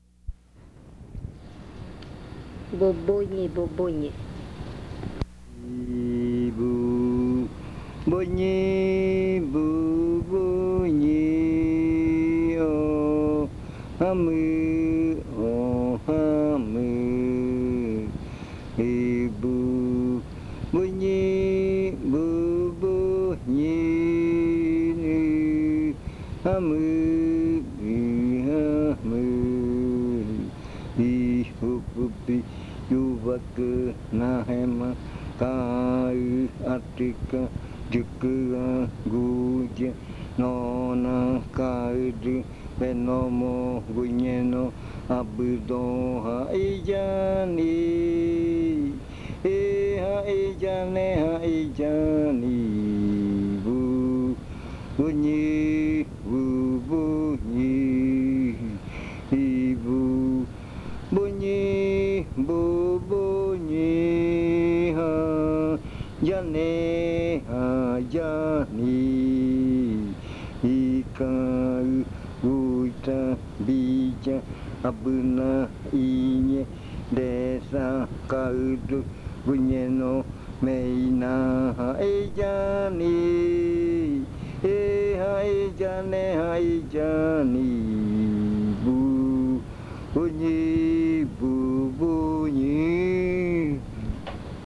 Leticia, Amazonas
Canción de madrugada. 4:00 AM. Canción satírica.
Lo cantan los murui del clan chucha (jeiaɨ).
Early morning chant. 4:00 AM. Satirical chant. This chant is for when they return from the dance hungry and they didn't give them food (bubuñɨ: stingy). It is sung by the Murui of the Chucha clan (jeiaɨ).